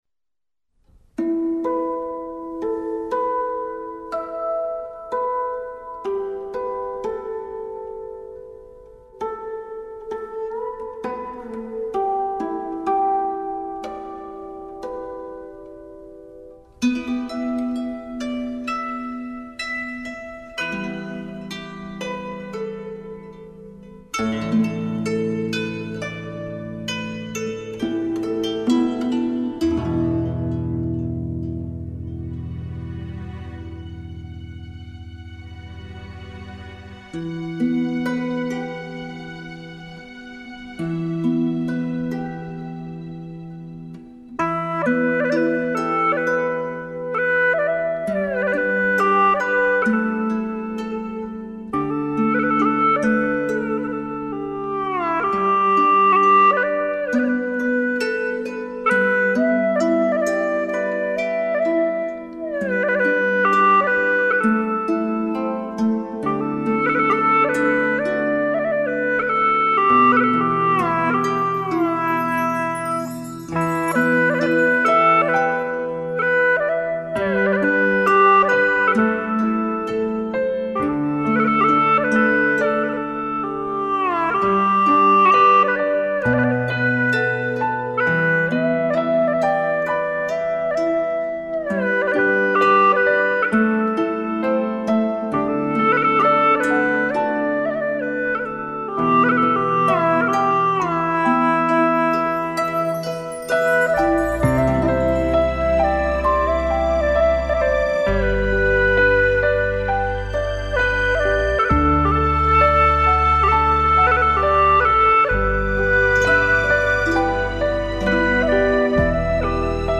调式 : G 曲类 : 古风